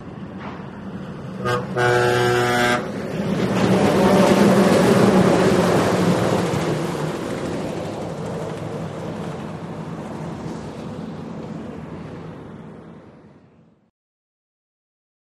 Monorail, Disneyland, With Horn